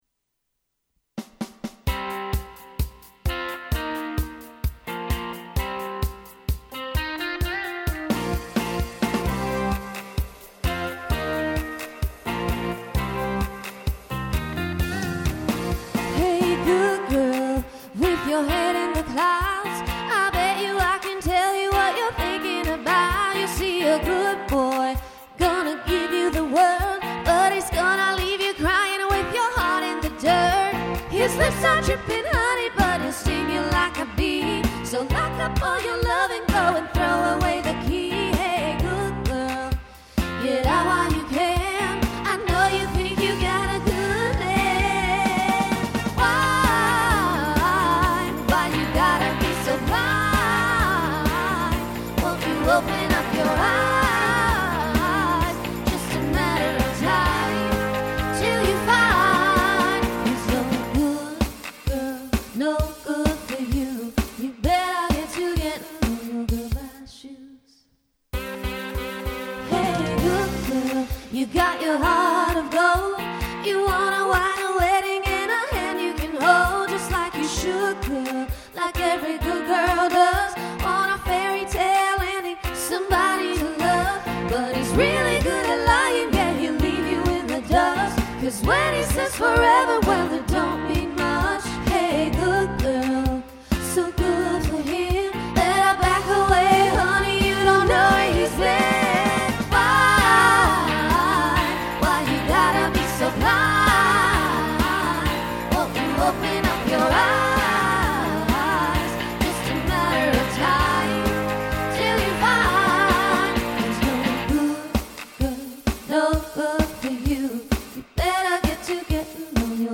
Voicing SSA Instrumental combo Genre Country